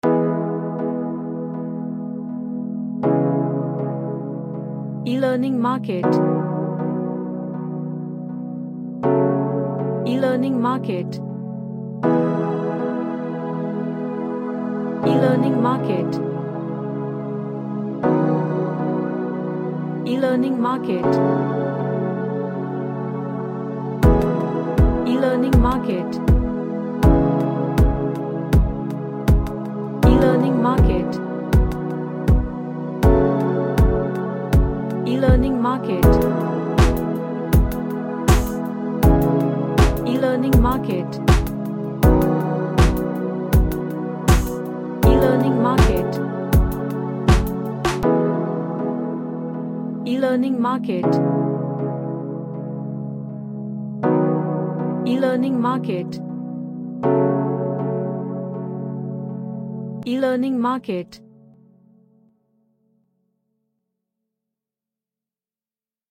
A padded track with dark piano.
Relaxation / Meditation